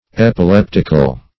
Epileptical \Ep`i*lep"tic*al\, a.